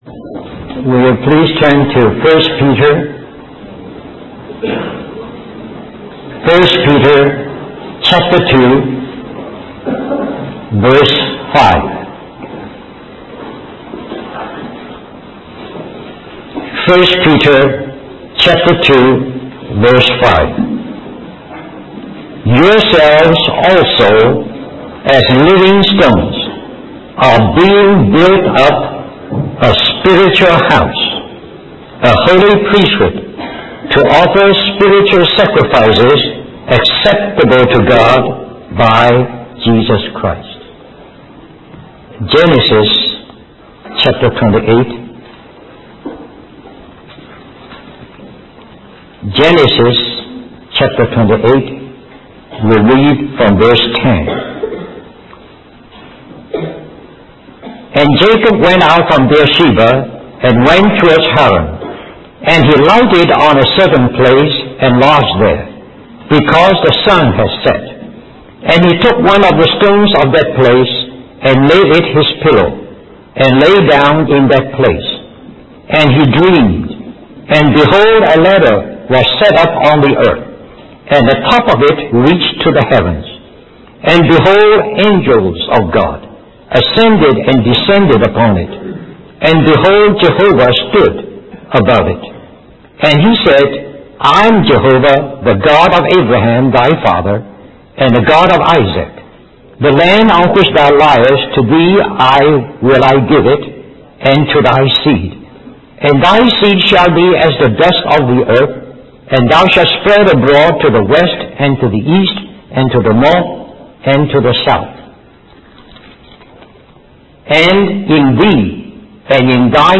In this sermon, the preacher discusses the concept of the house of God as the union and communion of God and man in Christ Jesus. He refers to the story of Jacob's dream in Genesis, where Jacob sees a ladder reaching to heaven with angels ascending and descending upon it.